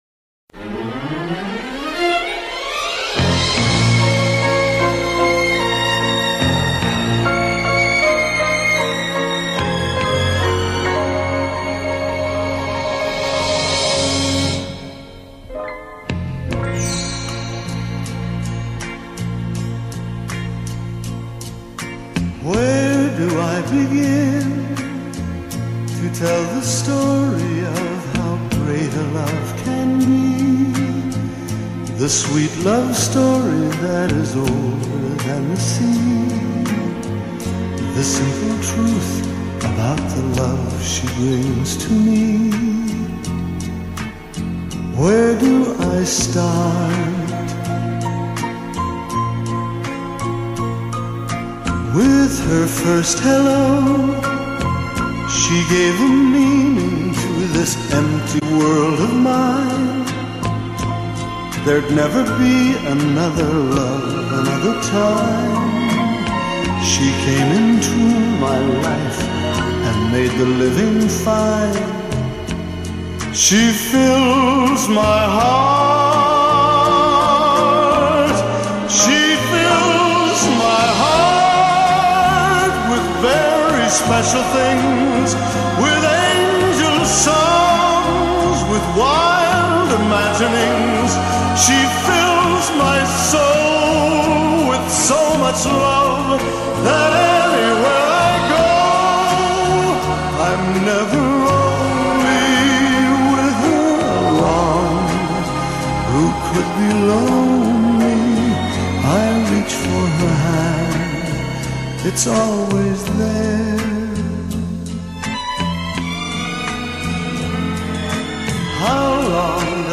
English Song